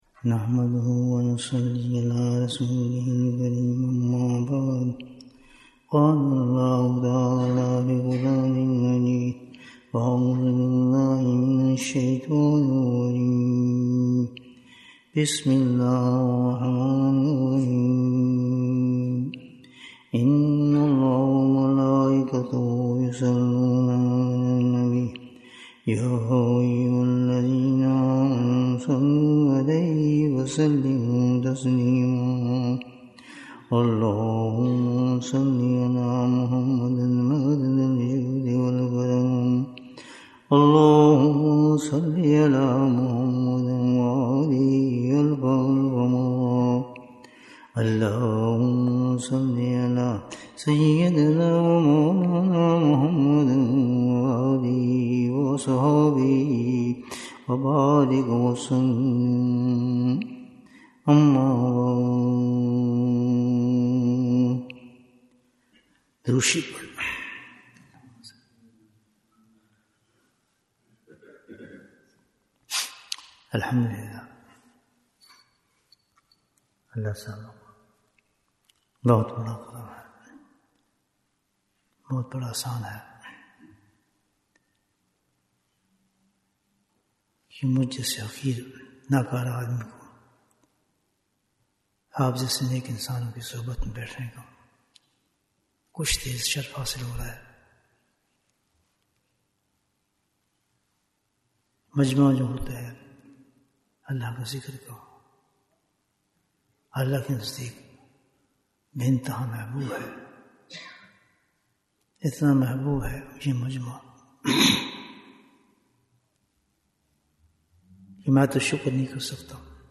Bayan, 102 minutes 12th December, 2024 Click for English Download Audio Comments What Is Imaan?